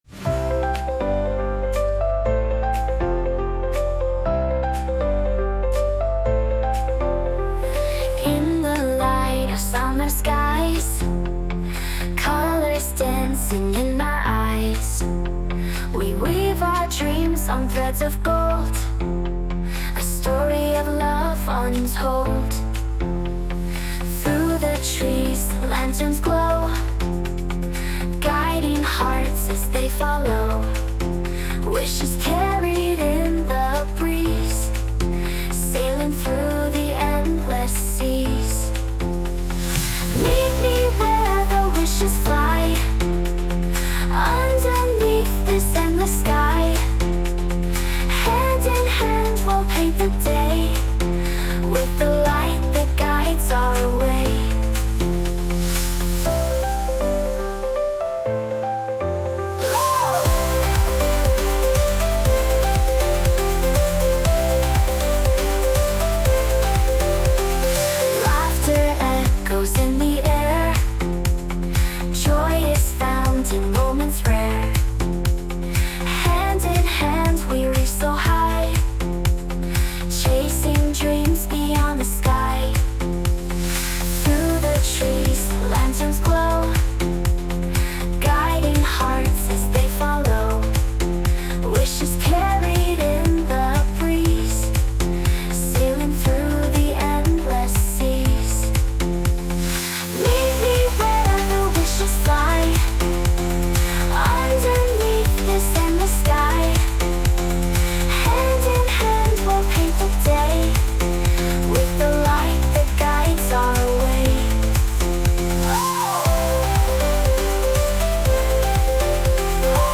洋楽女性ボーカル著作権フリーBGM ボーカル
女性ボーカル（洋楽）曲です。